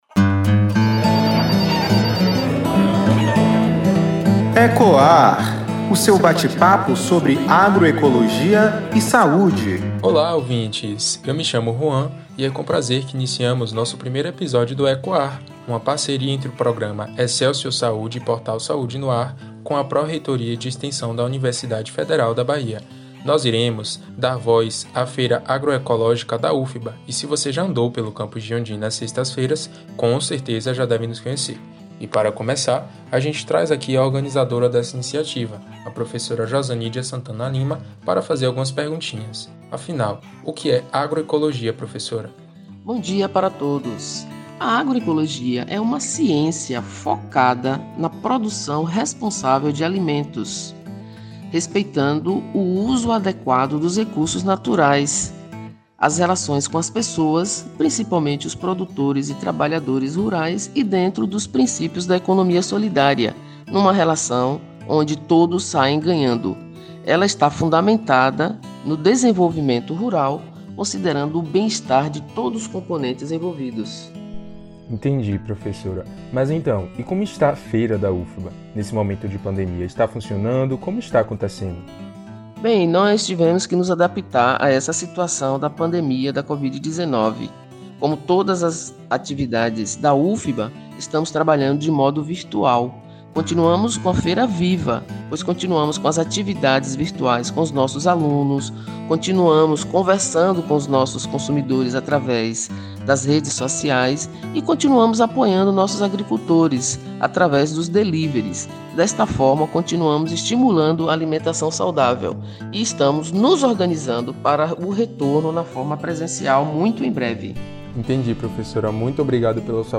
Segundo a rica entrevista do podcast, ela trouxe concepções importantes sobre a motivação da Professora ao projetar a escrita do livro, além de explicitar qual o legado essa publicação deixa aos seus leitores.